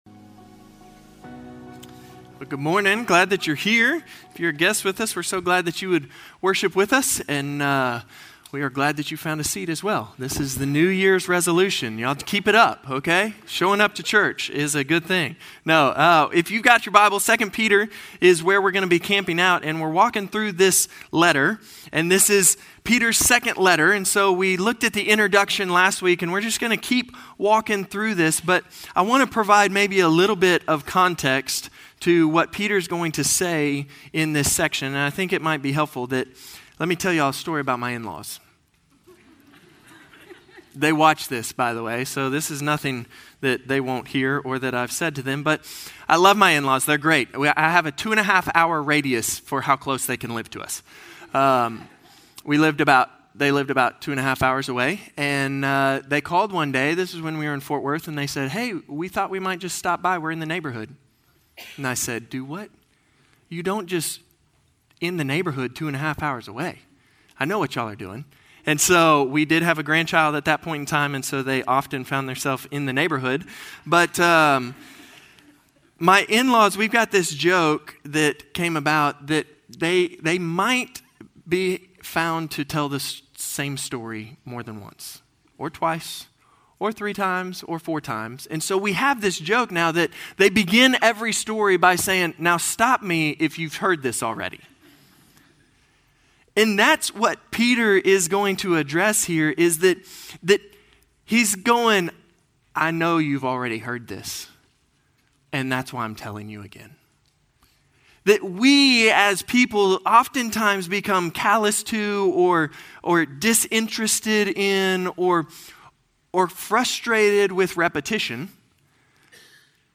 Norris Ferry Sermons